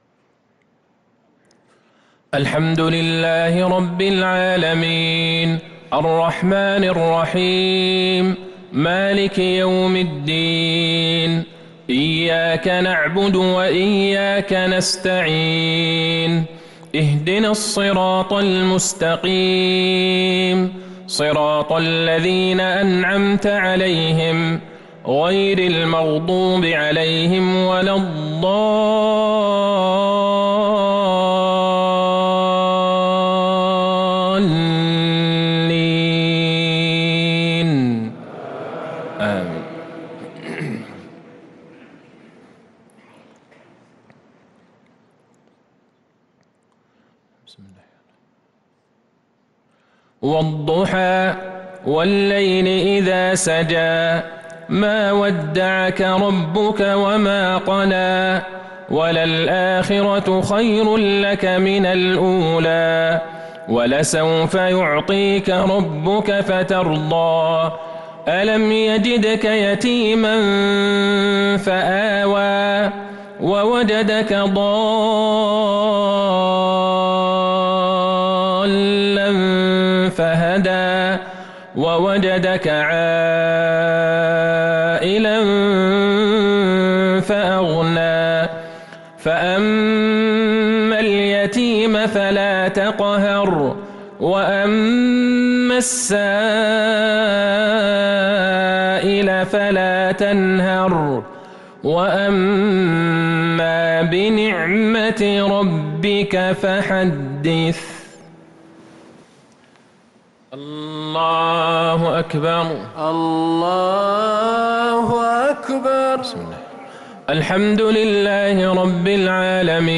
صلاة المغرب للقارئ عبدالله البعيجان 5 رمضان 1443 هـ
تِلَاوَات الْحَرَمَيْن .